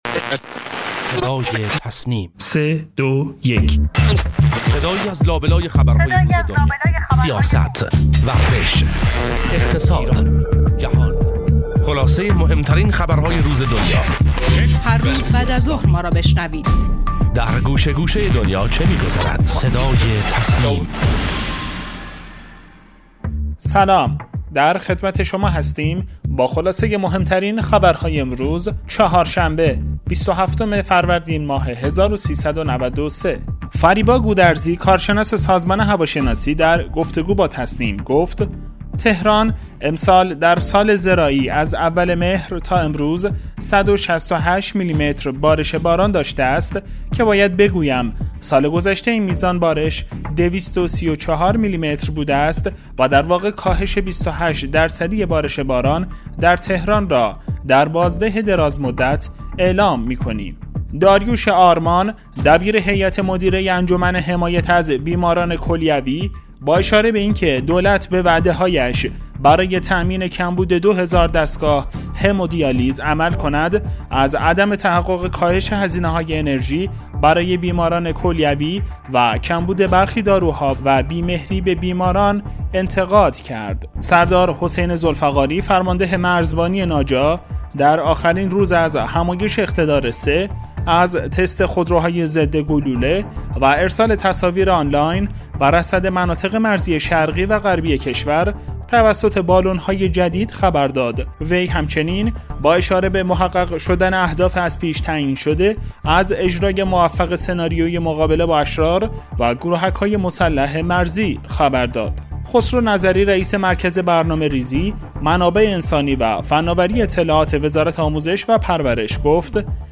خبرگزاری تسنیم: مهمترین اخبار و گزارشات درباره موضوعات داخلی و خارجی امروز را از «صدای تسنیم» بشنوید.